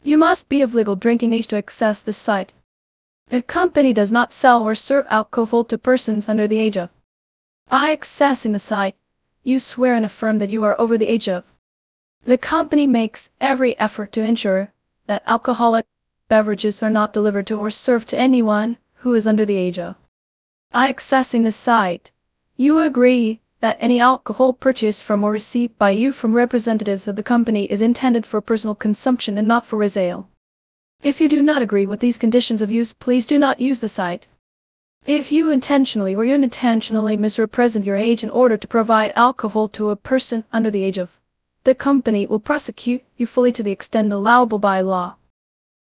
EZ-Voice-Clone-From-Long-Text